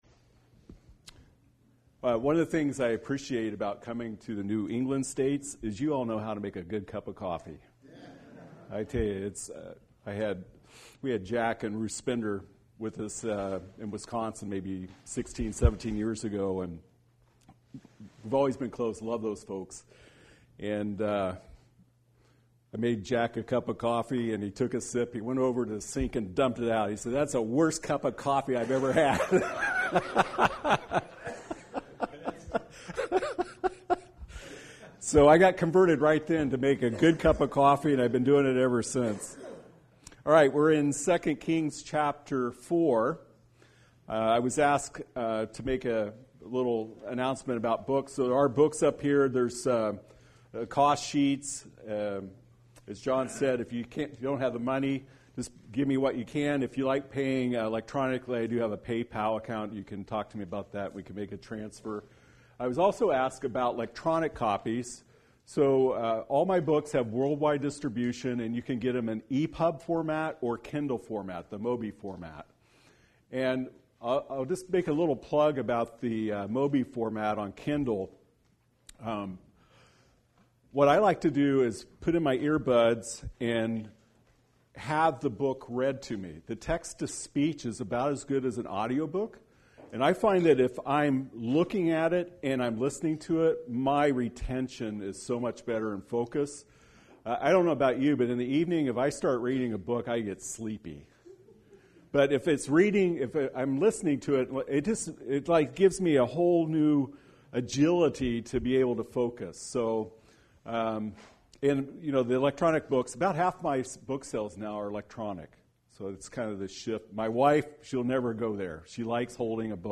Listen to Weekly Message
Series: Spring Bible Conference